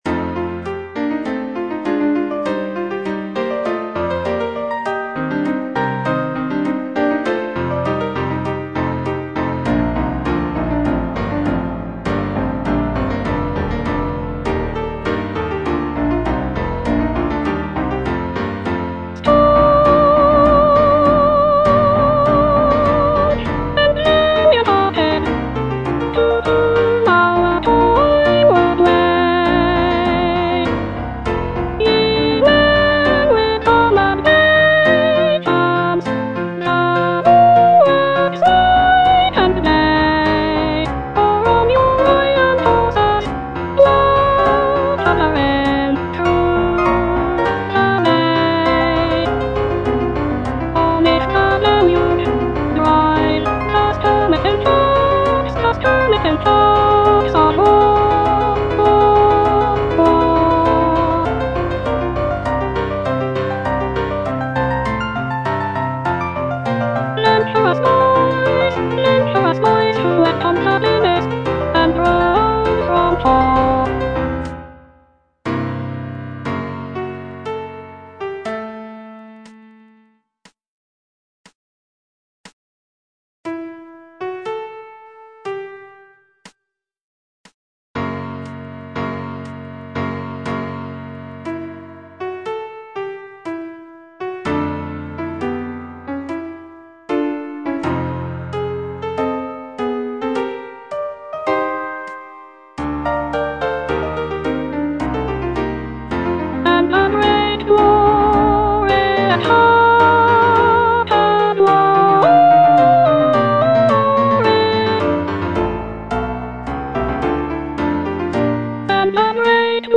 C.H.H. PARRY - THE CHIVALRY OF THE SEA Staunch and valiant-hearted (soprano II) (Voice with metronome) Ads stop: auto-stop
"The Chivalry of the Sea" is a choral work composed by C.H.H. Parry.
With its soaring melodies and powerful harmonies, the music captures the spirit of heroism and pays homage to those serving at sea.